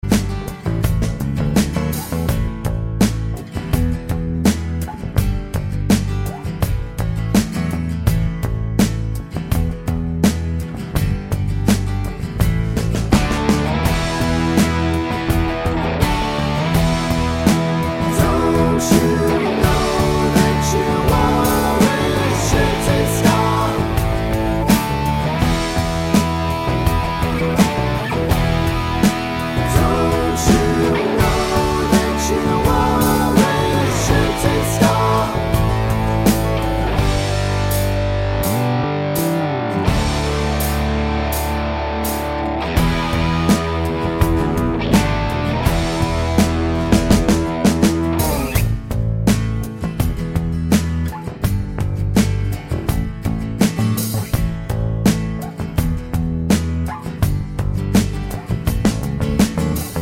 no Backing Vocals Rock 6:17 Buy £1.50